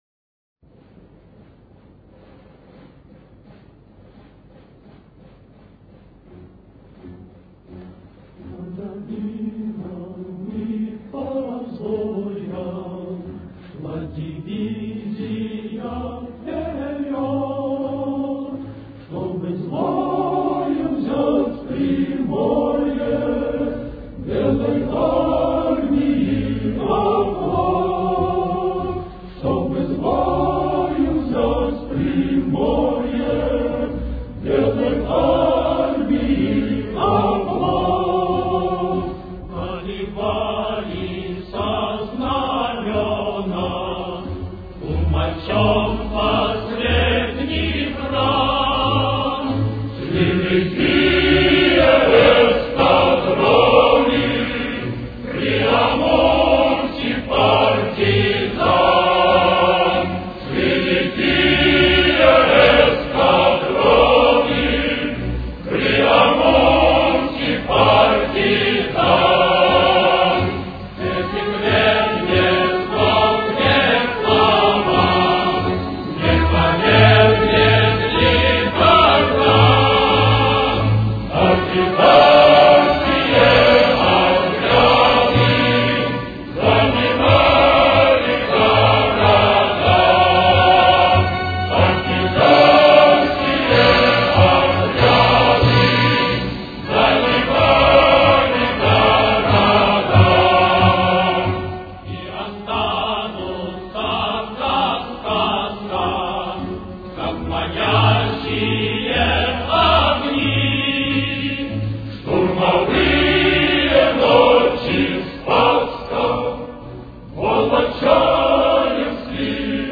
Фа-диез минор. Темп: 95.